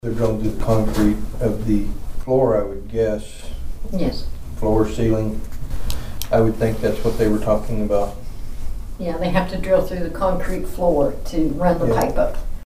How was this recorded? The Nowata County Commissioners met on Monday morning for a reguarly scheduled meeting at the Nowata County Annex.